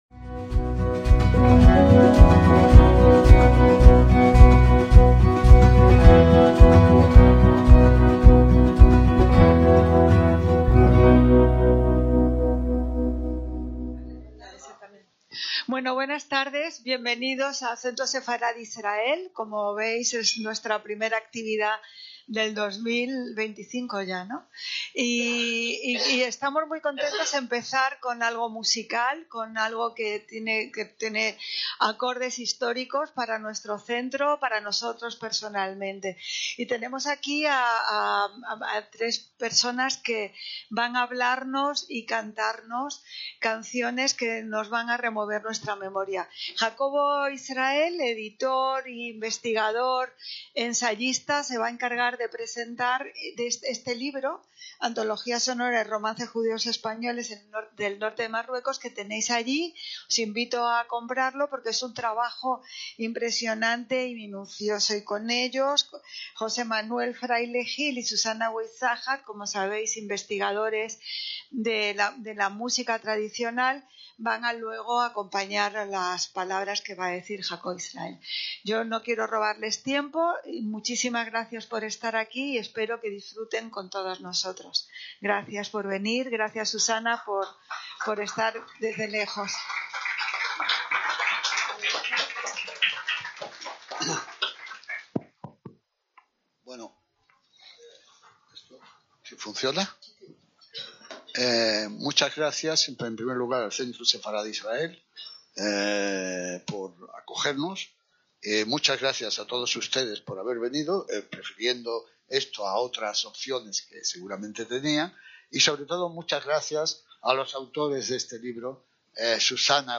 ACTOS EN DIRECTO
una conferencia sobre el romancero sefardí de Marruecos